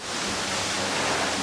hose.wav